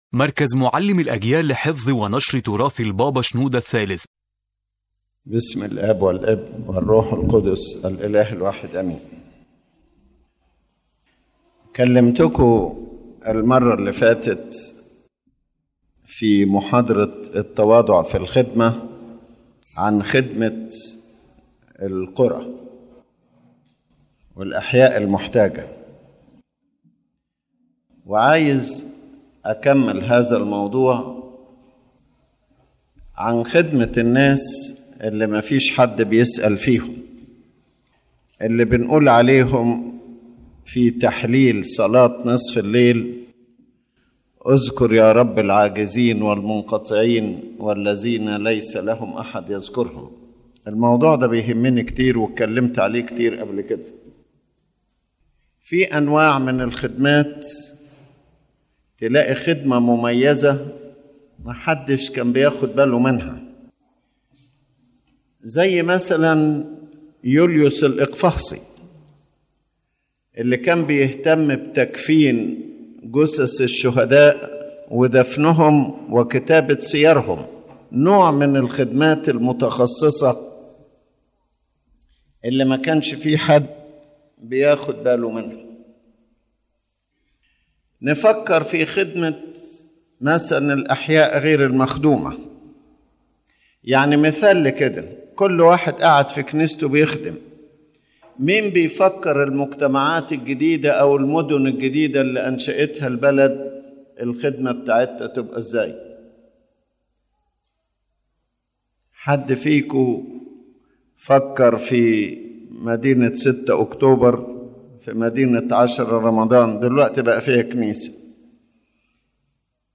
Groups in need of special ministry The lecture lists various groups: new neighborhoods and cities, the quarters and alleys of Cairo, street children, garbage collectors, beggars, addicts, the delinquent, the elderly and the disabled, the deaf and mute, prisoners and their families, girls working in factories, young divorced women, and also people of position and wealth who are ashamed to approach spiritually.